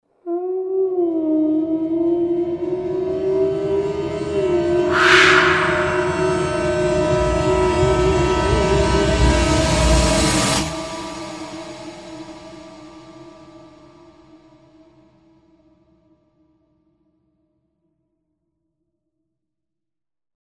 Sound Effect Horror Intro 3.mp3